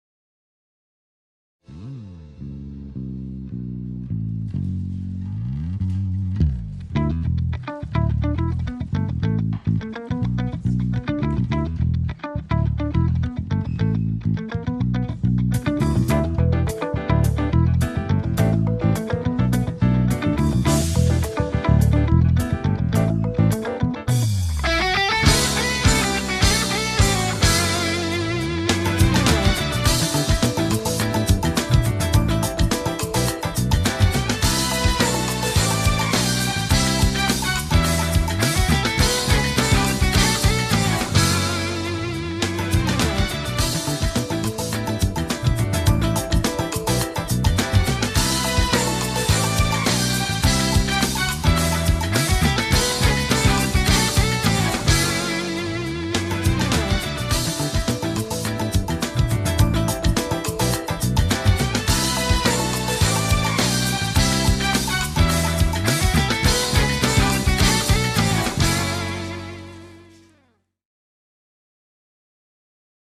neşeli eğlenceli enerjik fon müziği.